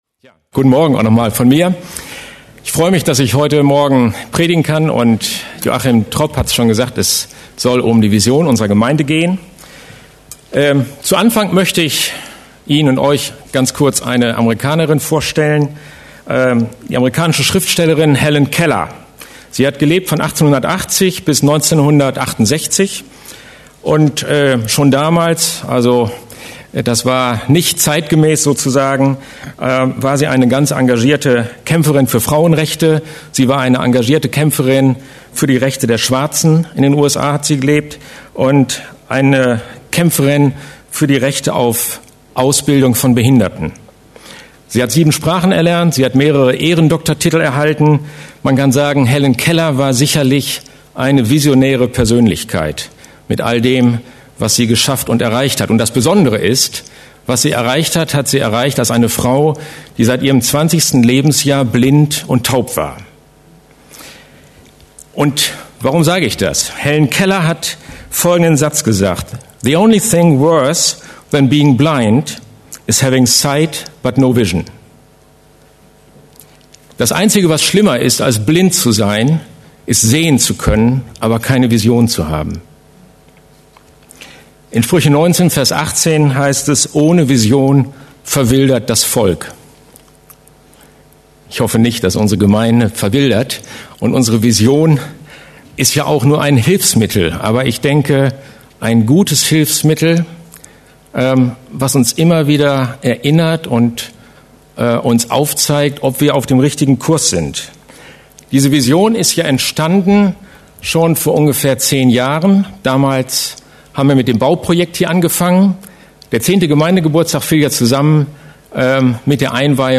November 2015 …und werden zu hingegebenen Nachfolgern Christi Prediger